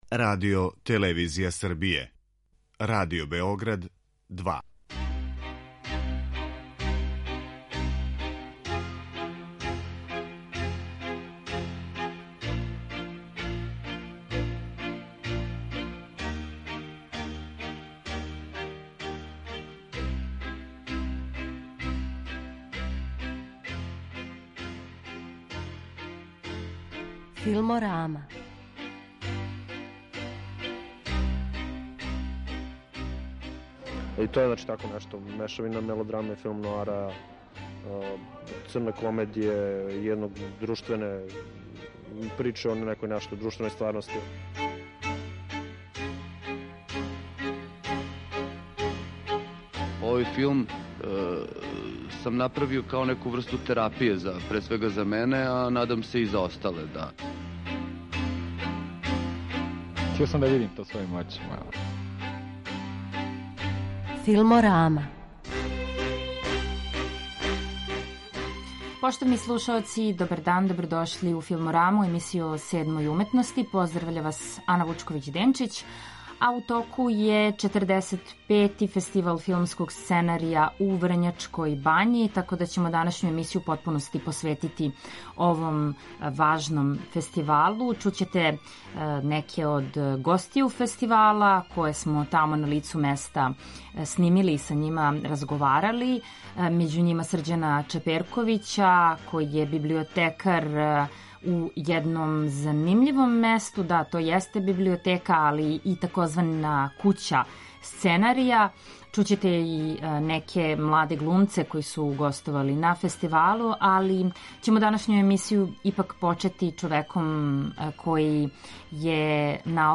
Зато ће данашња емисија бити посвећена управо теми сценарија као предлошка, идеје или можда чак и аутономног уметничког дела. Покушаћемо то да откријемо у разговору са гостима